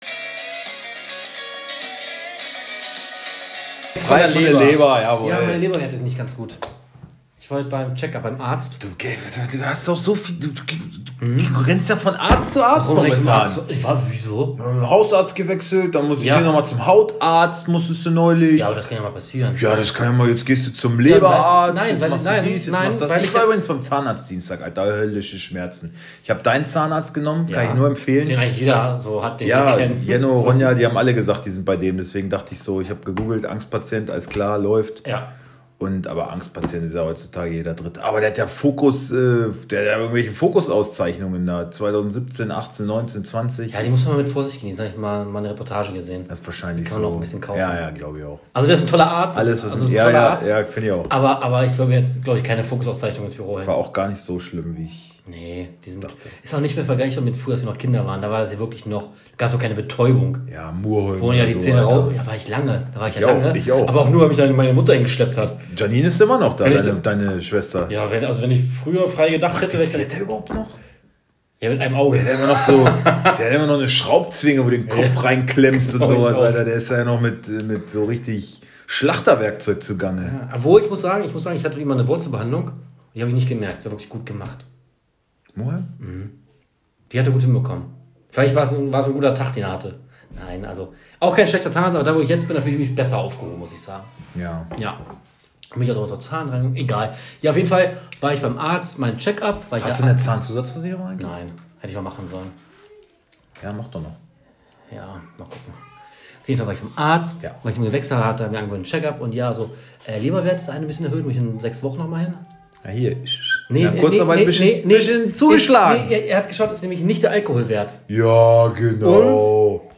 Wir hoffen euch damit auch bei eurer eigenen Kickbase-Aufstellung weiter helfen zu können. Ausserdem blicken wir auch kritisch und analytisch auf den vergangenen Spieltag und kommentieren volksnah, mit Esprit, Härte, Witz und der nötigen Ironie.